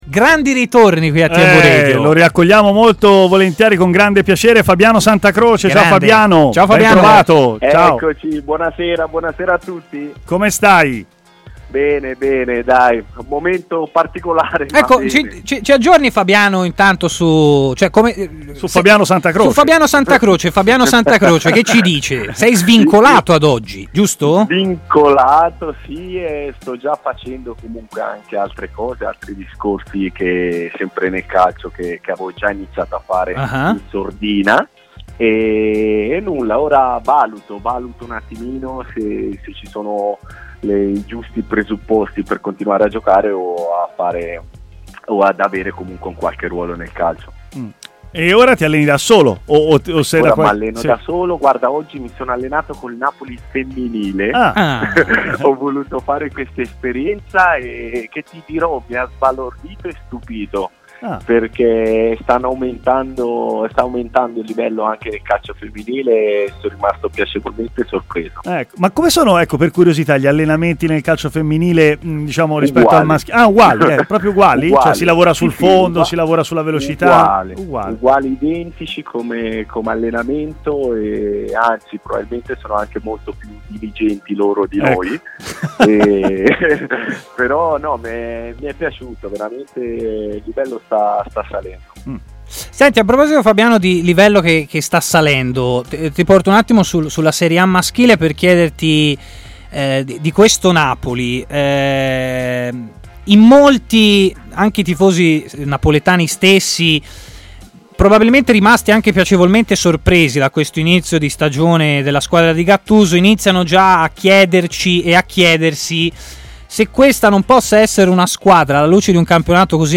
Il difensore Fabiano Santacroce si è collegato in diretta con TMW Radio, intervenendo nel corso della trasmissione Stadio Aperto